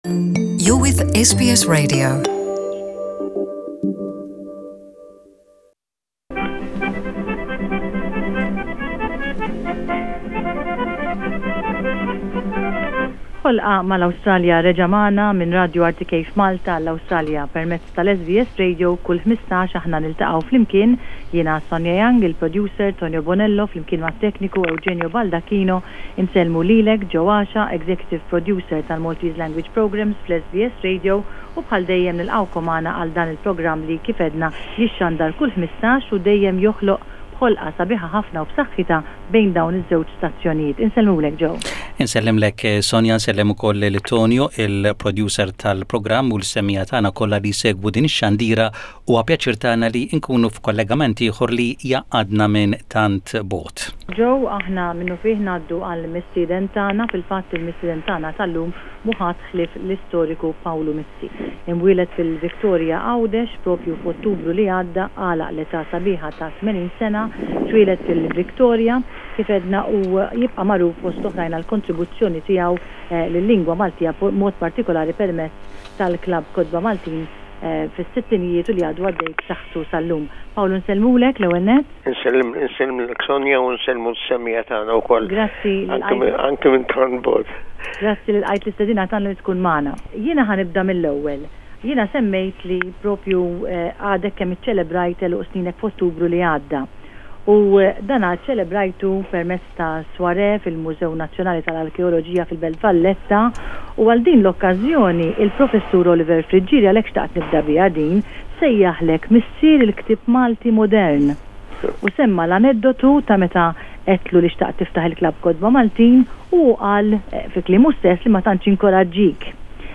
interview
during the fortnightly live link up, between SBS Radio in Melbourne and RTK in Malta in January 2010